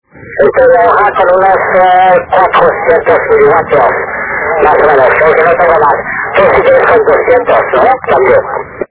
Archivos sonido de QSOs en 10 GHz SSB
398 Kms Tropo Mar